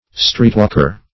Streetwalker \Street"walk`er\, n.